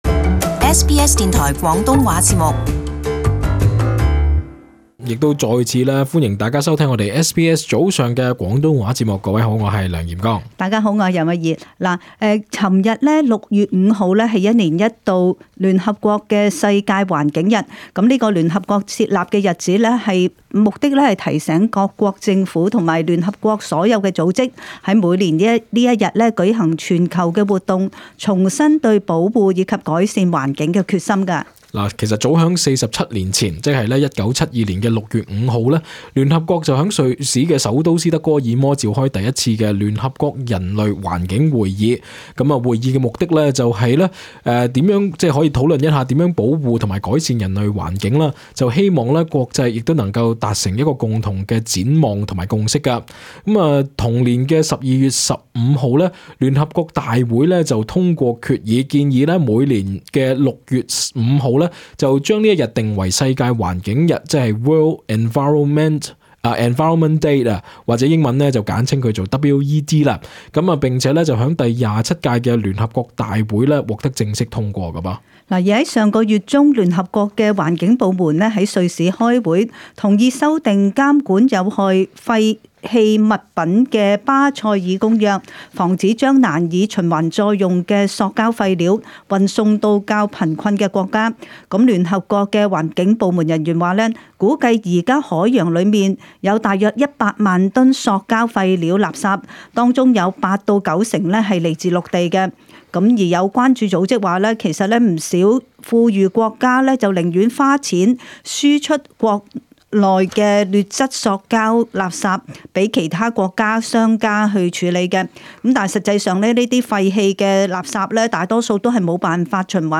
與聽眾一起討論